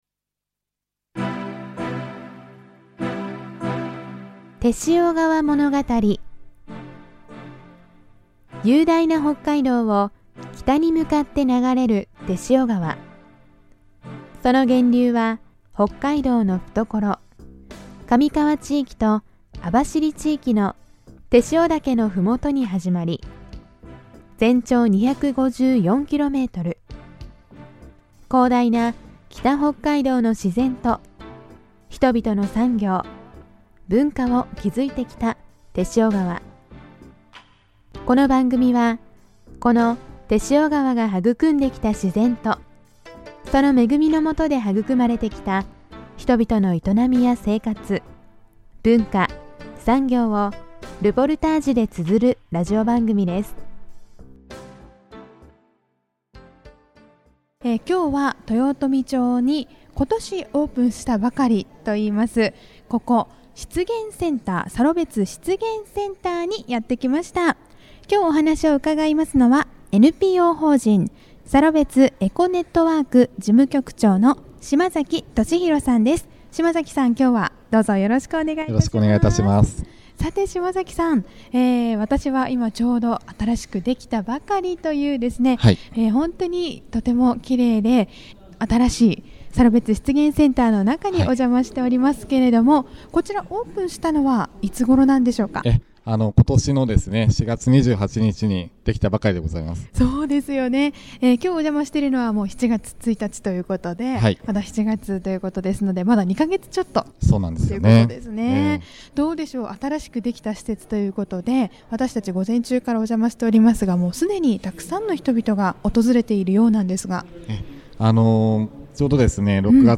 豊富町にお邪魔し、利尻礼文サロベツ国立公園の新たな拠点として平成２３年４月２８日にオープンしました「サロベツ湿原センター」にて、湿原を歩きながら、この季節の自然や、泥炭の歴史などについてお話を伺いました。